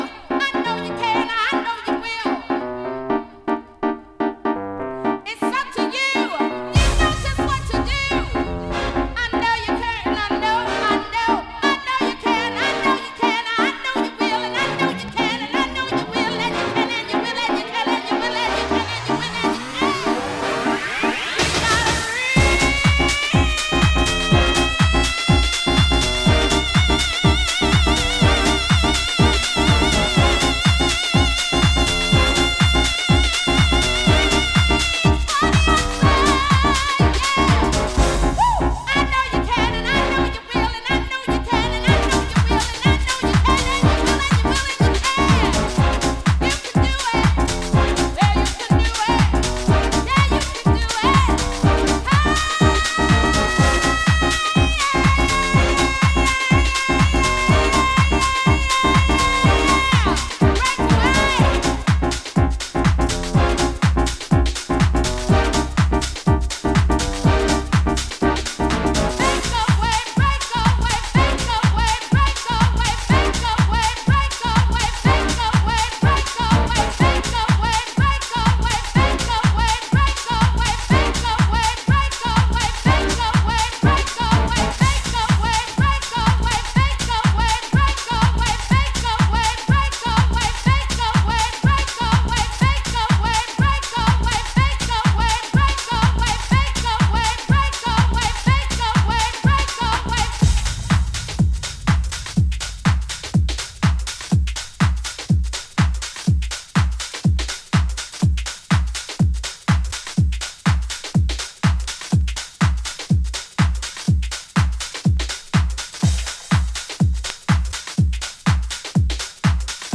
vocal mix